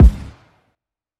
stackstomp_kick.wav